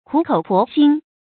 注音：ㄎㄨˇ ㄎㄡˇ ㄆㄛˊ ㄒㄧㄣ
苦口婆心的讀法